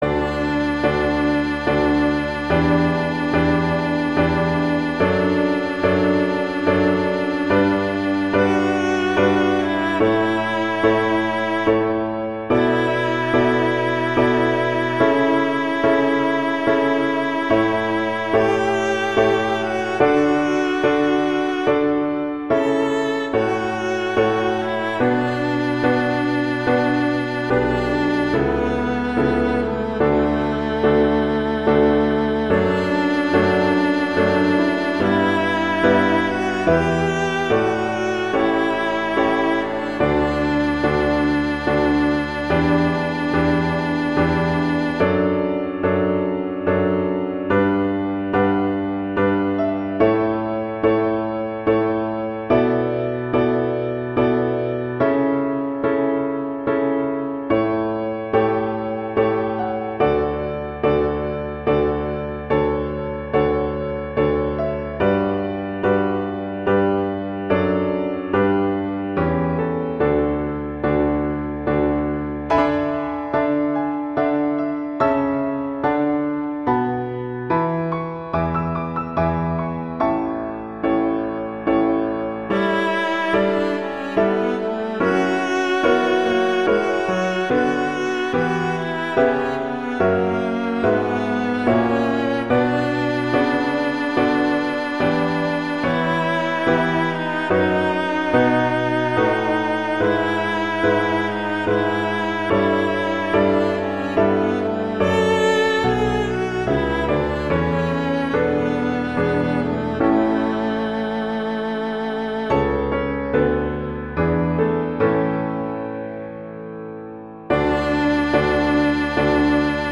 arrangements for viola and piano